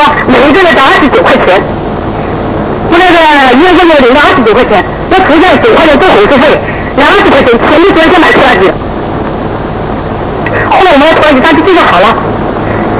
雷锋声音